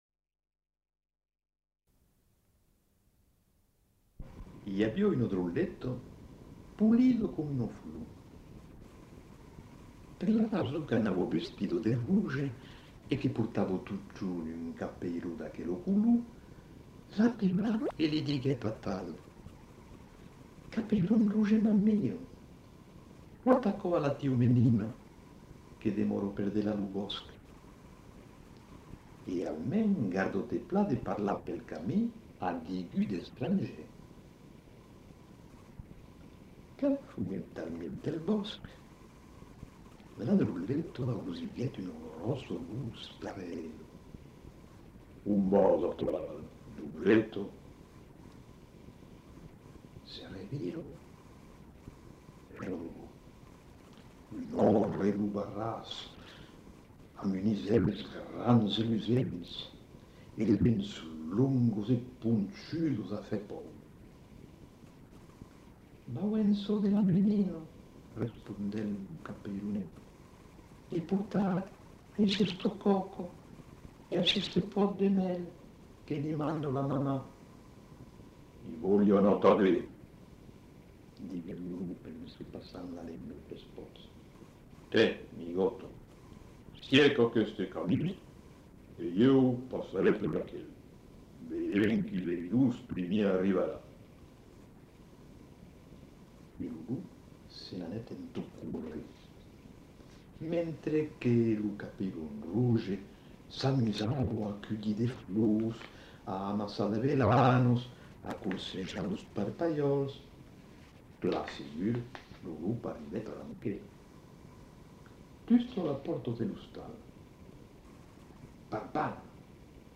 Genre : conte-légende-récit
Type de voix : voix d'homme
Production du son : lu
Notes consultables : Défaut de lecture en début de séquence.